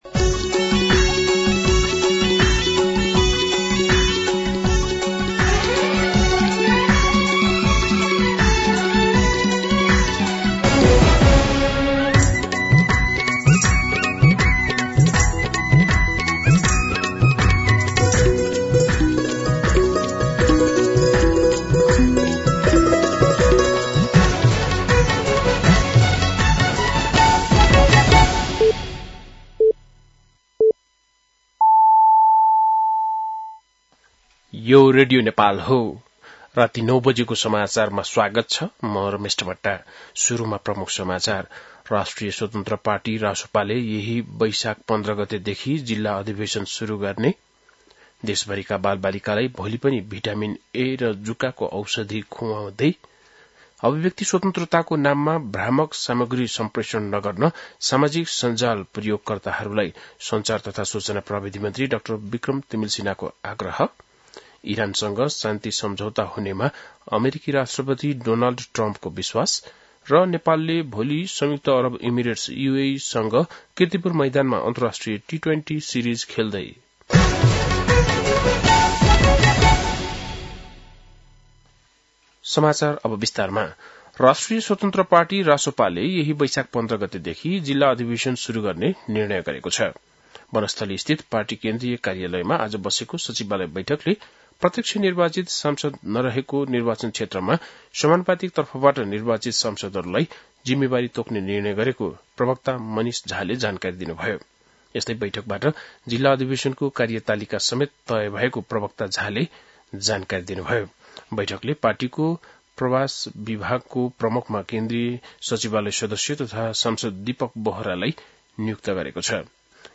बेलुकी ९ बजेको नेपाली समाचार : ६ वैशाख , २०८३
9-PM-Nepali-News-.mp3